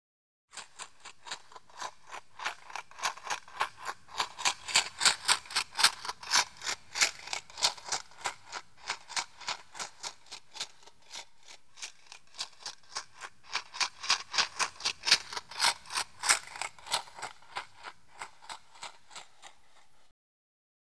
shake_h.wav